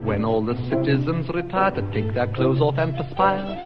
comic song